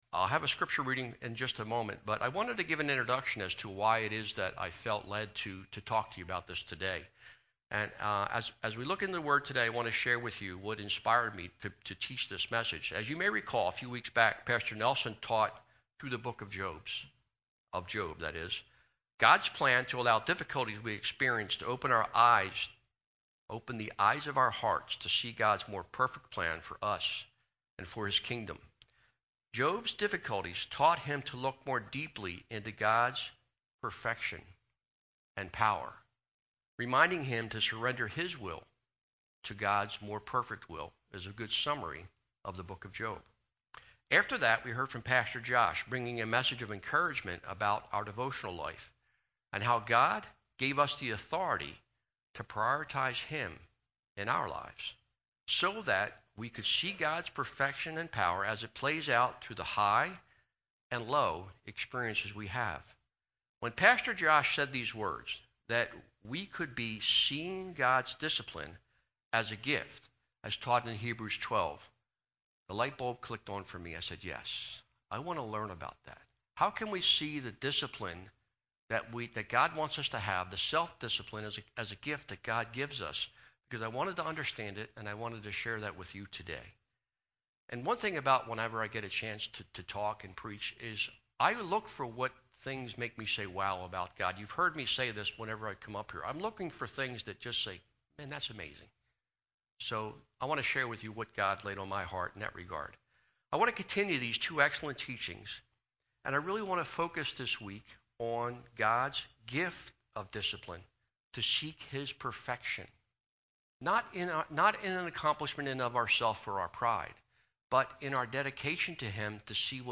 Sermon-09-05-21-JW-CD.mp3